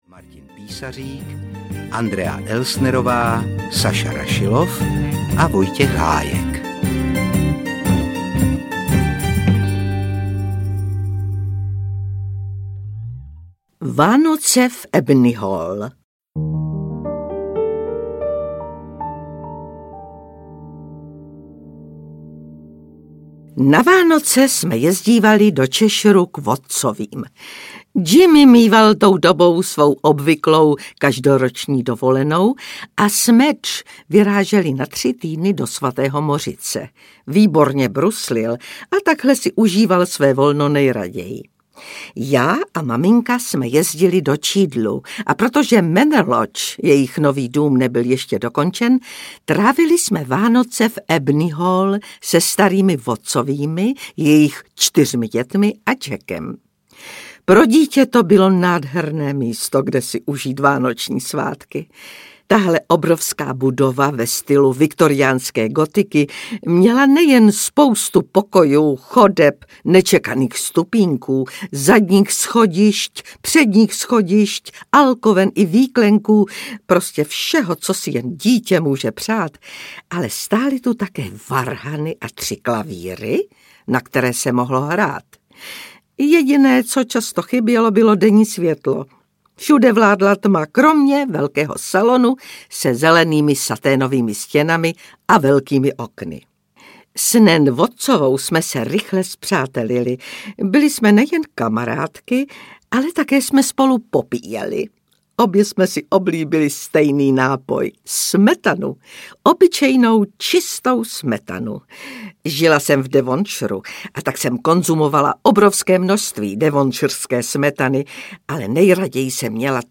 Ukázka z knihy
• InterpretRůžena Merunková, Petr Lněnička, Jaromír Meduna, Otakar Brousek ml.